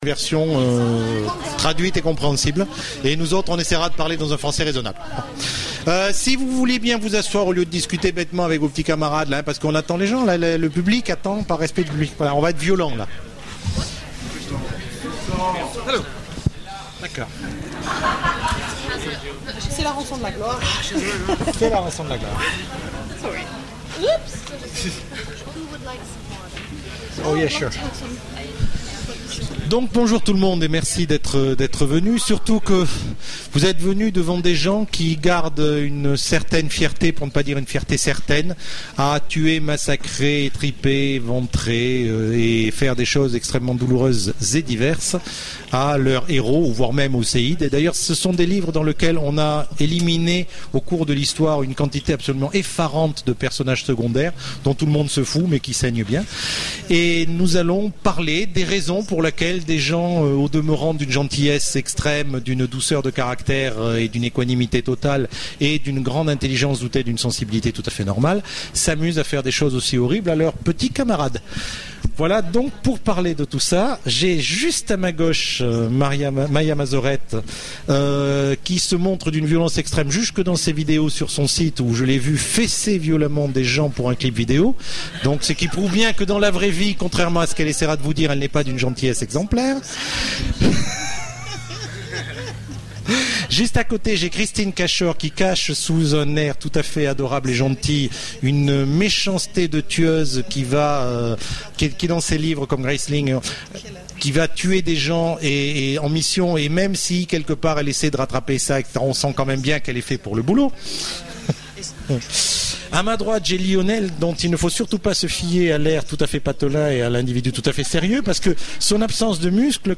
Voici l'enregistrement de la conférence De la sueur, du sang et des larmes… La fantasy, c’est parfois brutal ! aux Imaginal